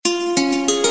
mother guitar